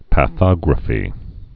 (pă-thŏgrə-fē)